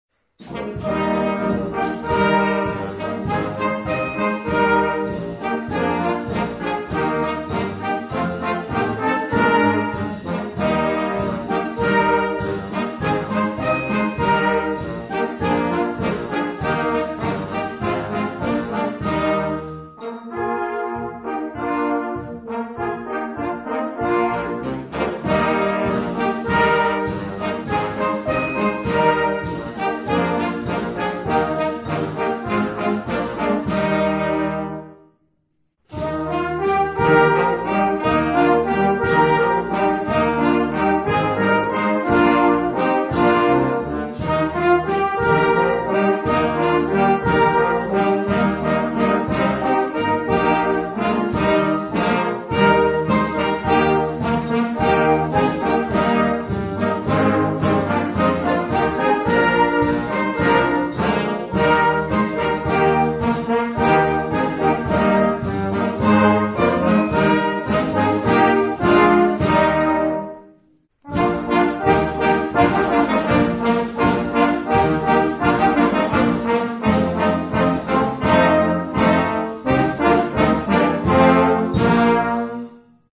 Noten für flexibles Ensemble, 4-stimmig + Percussion.